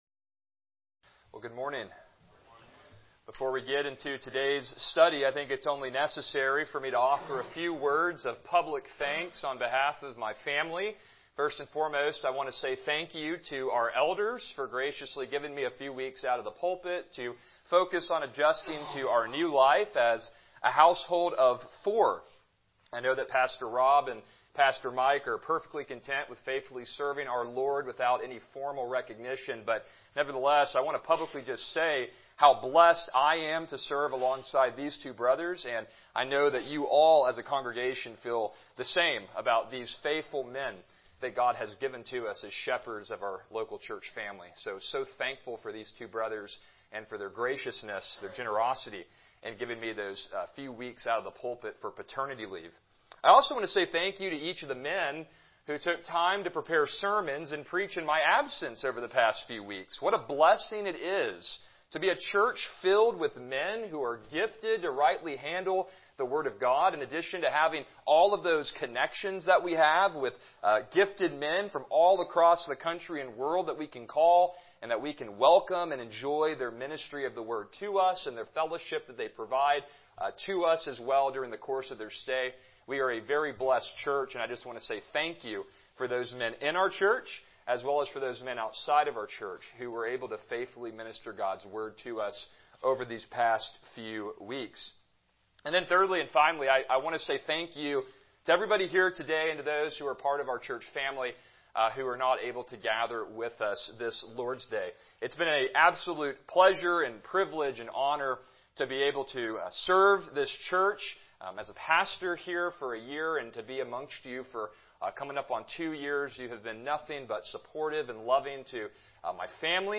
Passage: Titus 1:4 Service Type: Morning Worship